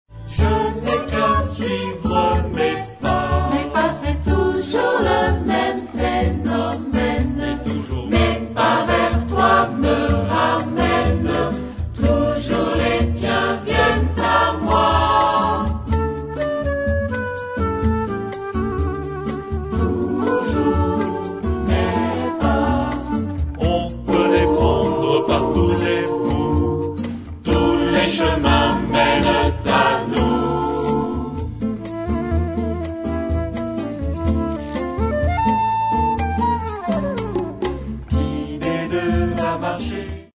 SATB (4 gemischter Chor Stimmen) ; Partitur.
Bossa nova. Choraljazz.
Charakter des Stückes: zweizeitig
Tonart(en): d-moll